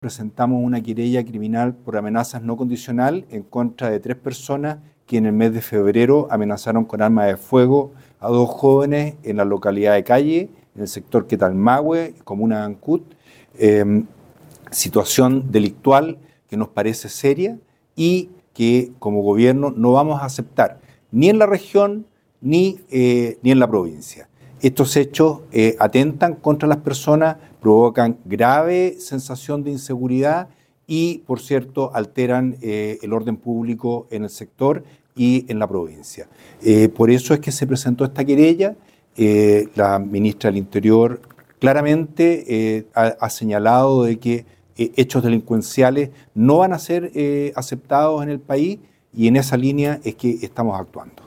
Fue el propio delegado presidencial provincial, Marcelo Malagueño, quien explicó la medida en el marco de una reunión desarrollada recientemente con los hombres de mar, autoridades locales, parlamentarias, entre otros.